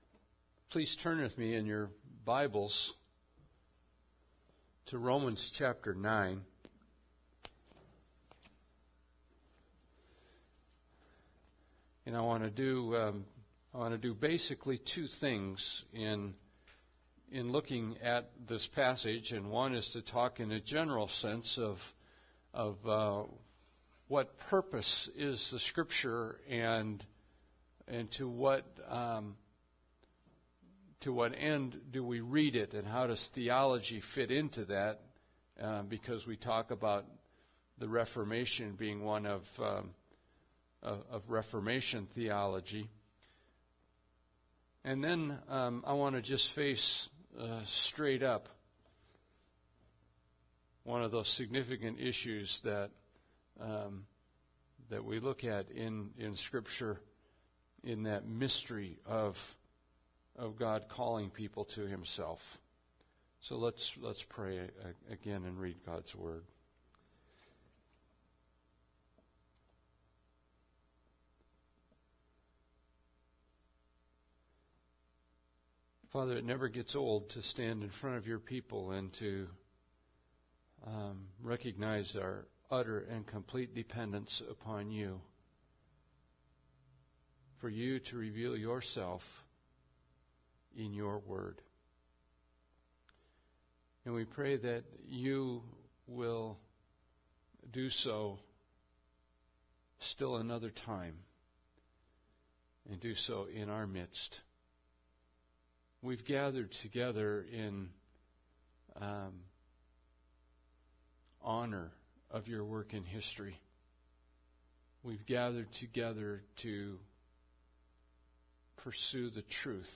Reformation Service